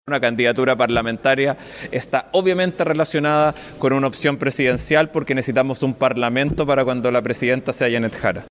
El senador Tomás de Rementería (PS) afirmó que si la falange no apoya a Jara, no podrán formar parte de una lista parlamentaria con la coalición.